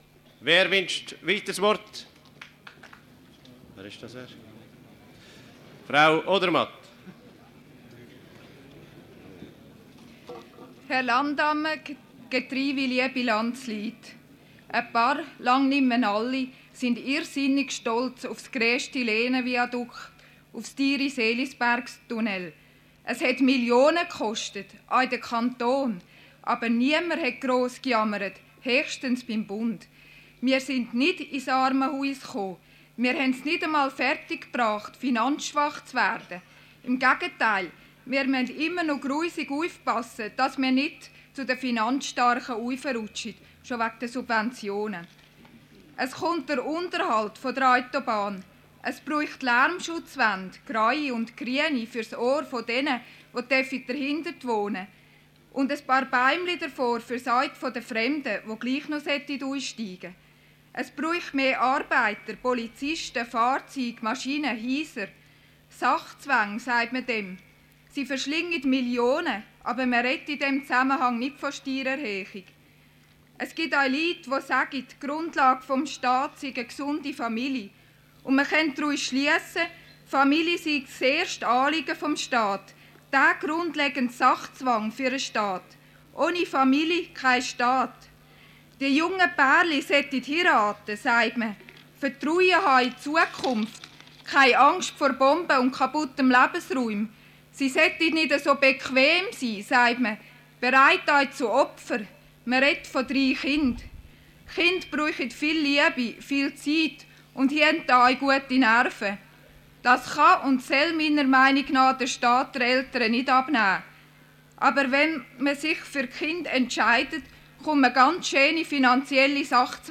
Landsgemeinde-Reden